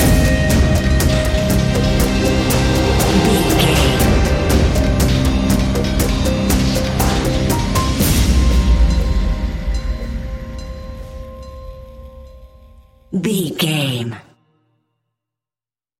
Fast paced
Thriller
Ionian/Major
dark ambient
EBM
synths